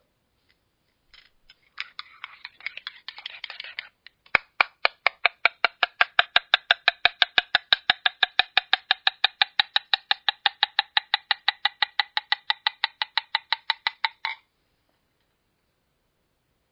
Tijdens het tikken hoor je een toon en deze toon wordt langzaam hoger.
Als je op de bodem tikt, dan hoor je de toon steeds hoger worden.
Als alle luchtbelletjes uit de vloeistof zijn verdwenen is de toon het hoogst en blijft hij gelijk.